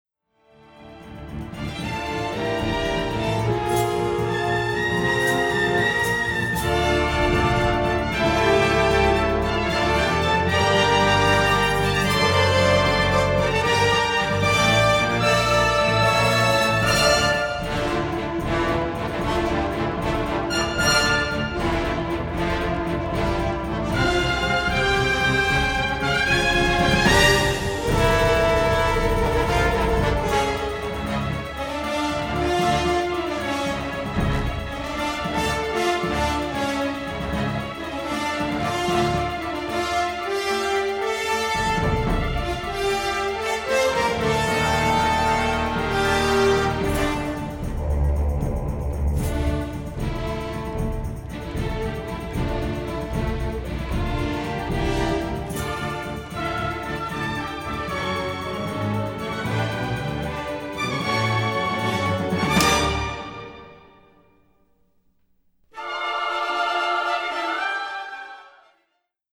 full of heartfelt themes, lilting waltzes, Indian flourishes